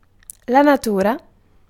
Ääntäminen
IPA : /ˈkʰæɹɨktɚ/